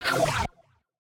error.ogg